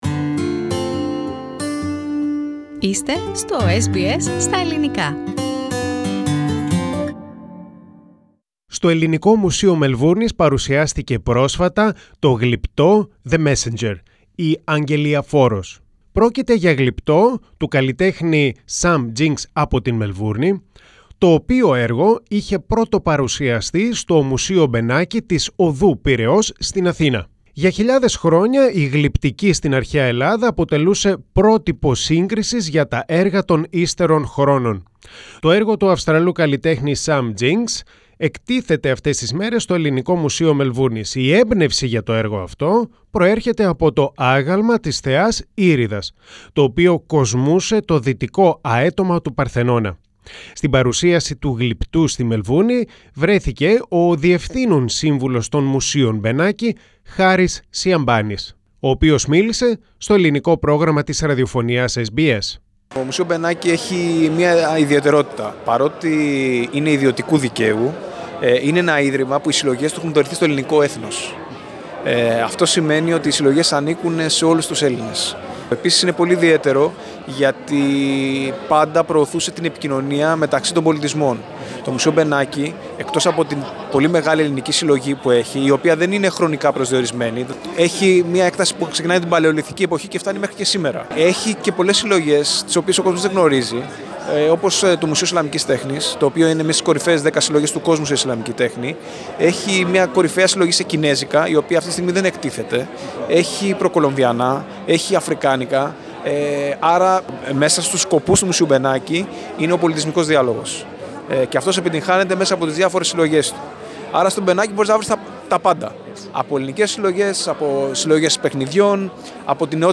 Το Ελληνικό Πρόγραμμα της Ραδιοφωνίας SBS βρέθηκε στα εγκαίνια της έκθεσης.